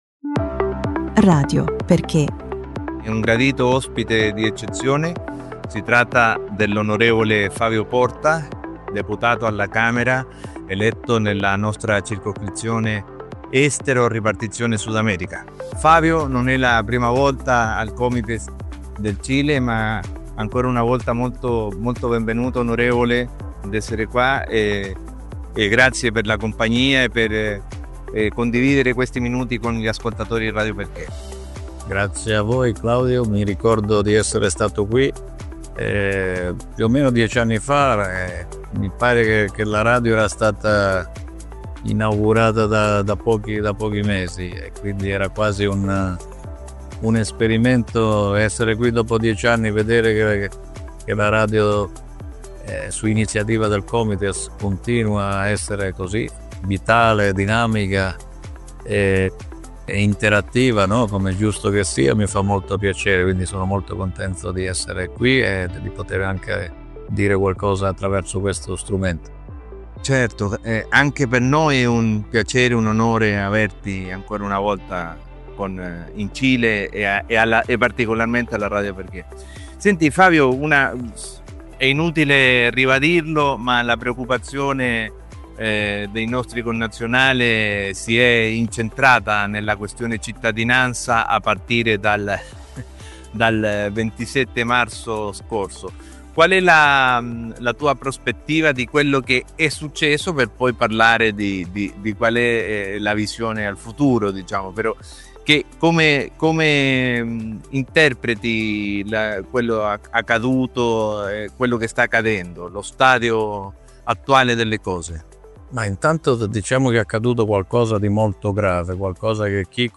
Entrevista con Fabio Porta
OK-ENTREVISTA-FAVIO-PORTA-MONTADO-PARA-RADIO-XKE-liv.mp3